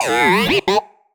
sci-fi_driod_robot_emote_03.wav